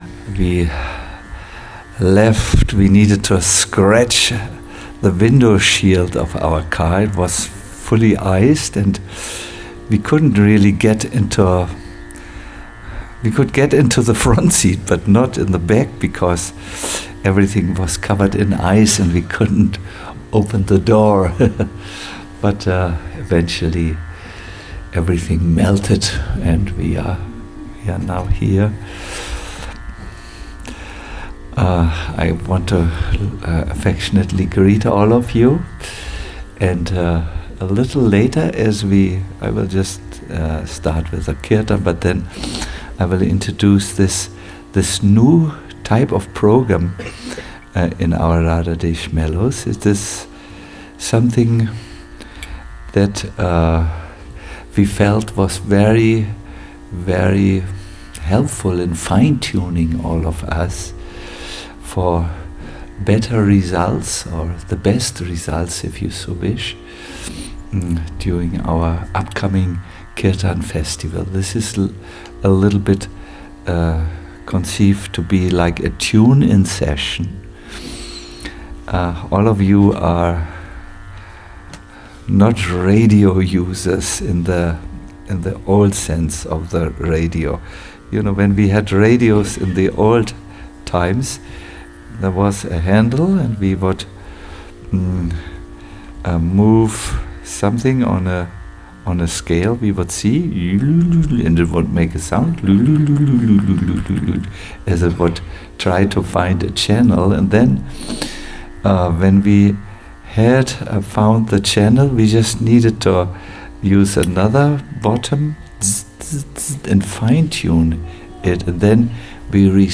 A lecture